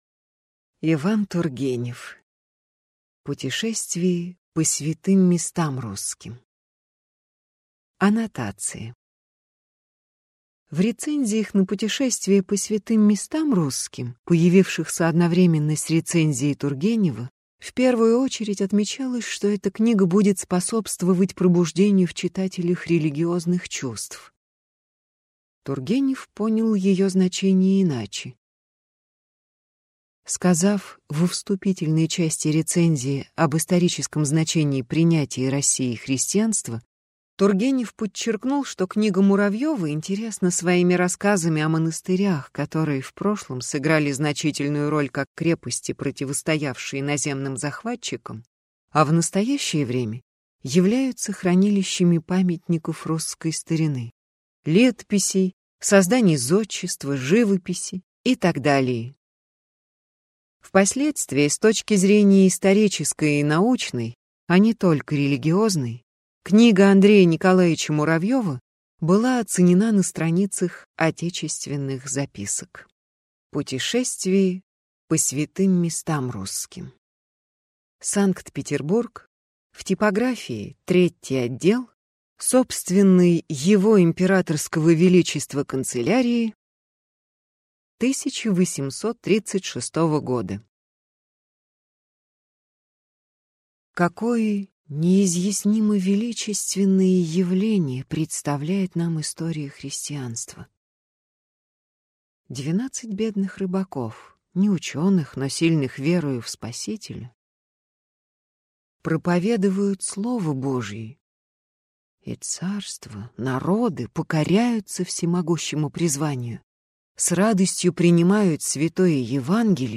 Аудиокнига Путешествие по святым местам русским | Библиотека аудиокниг